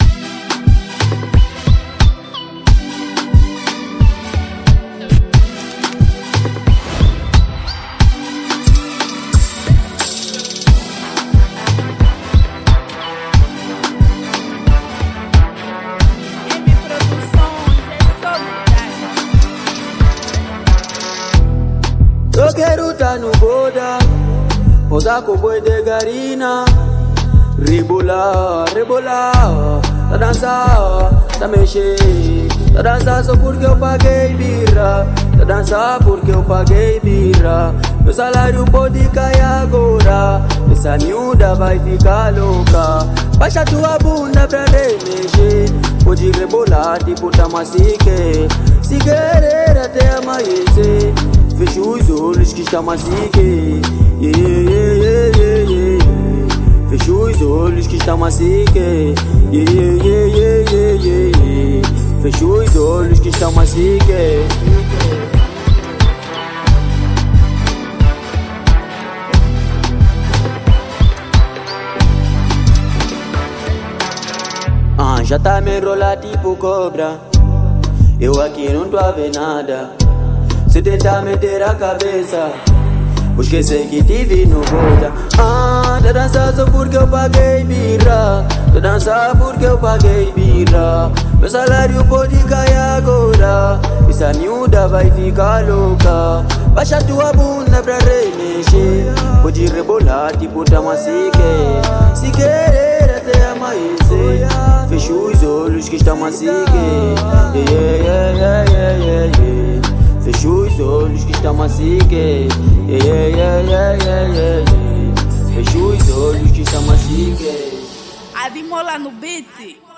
| Afro zouk